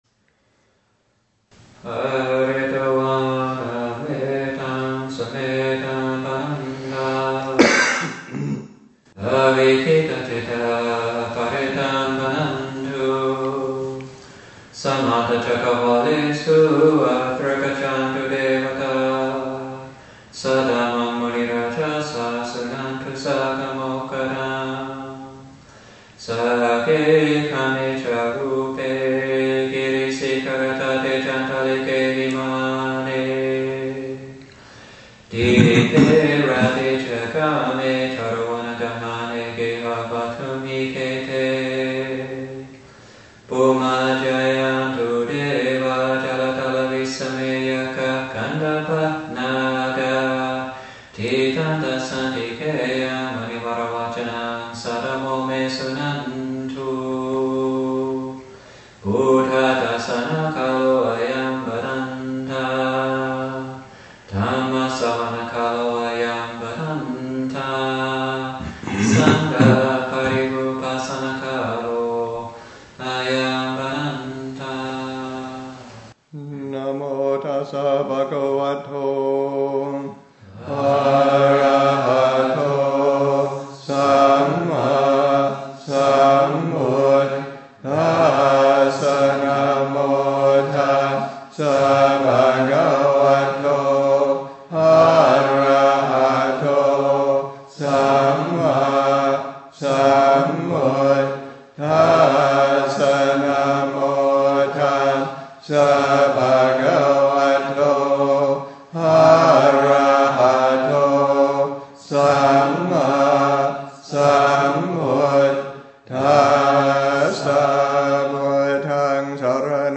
Protective chants
Chanting: Paritta chanting